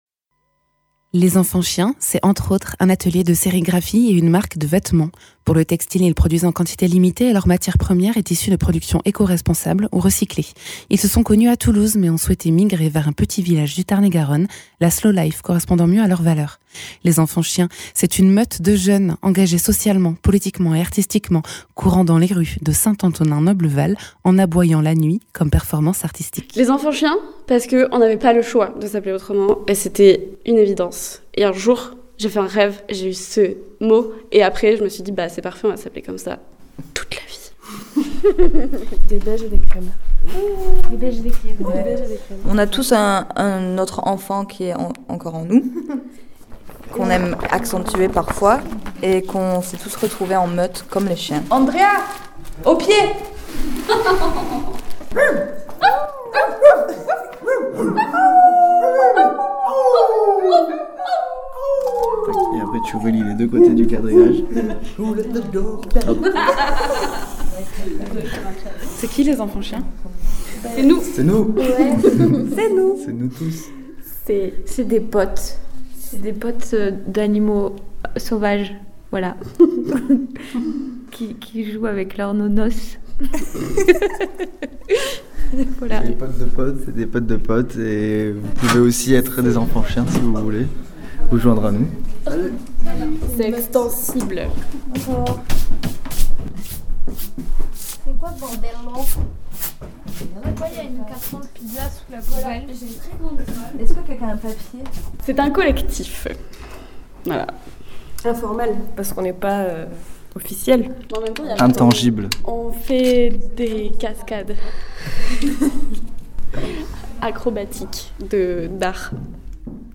Les Enfants chiens, c’est (entre autre) un atelier de sérigraphie et une marque de vêtements. Reportage dans leur atelier, au Bazart Textile de St Antonin Noble Val.
Interviews
Invité(s) : Les enfants chiens, collectif à Bazart